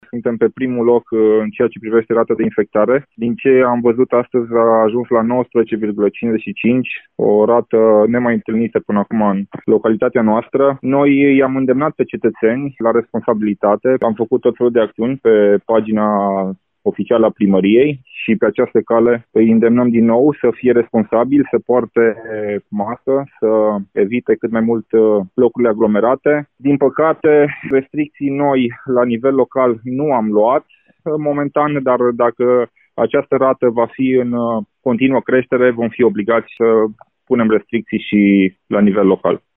Viceprimarul localităţii Remetea Mare, Cosmin Chira.